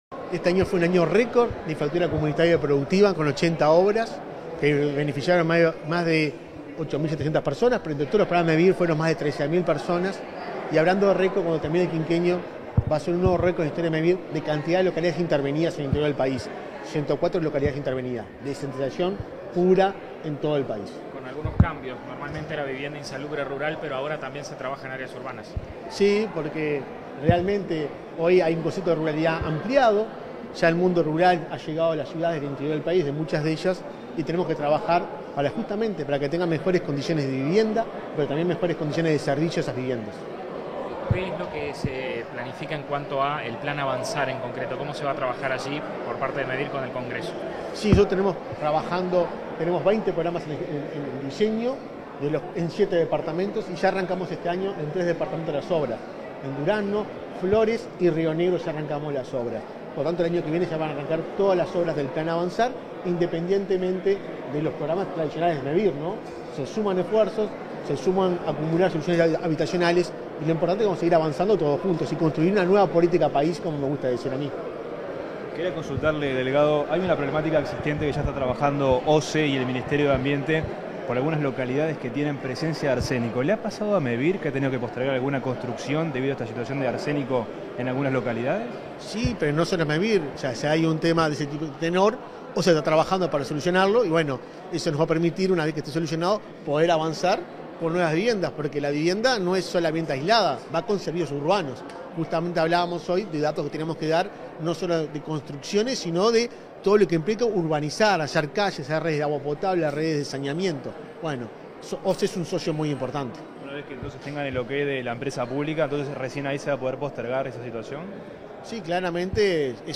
Declaraciones a la prensa del presidente de Mevir, Juan Pablo Delgado